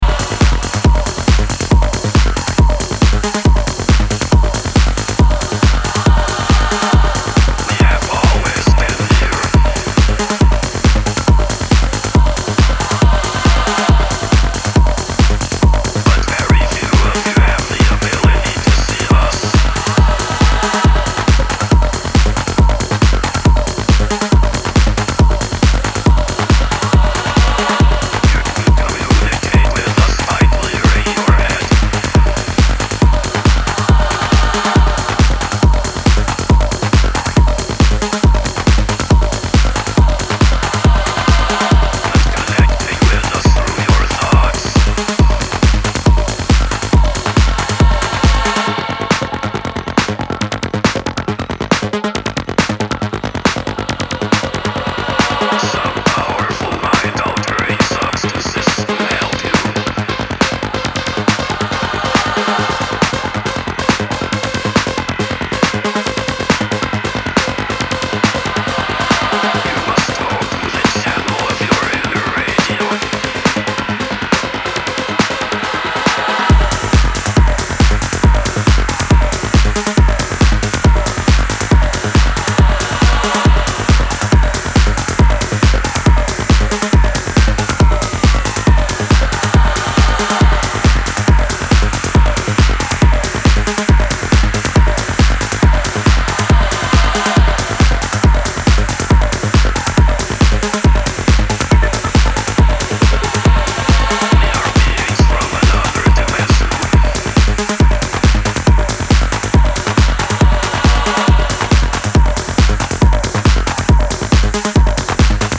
Four solid electro cuts dominate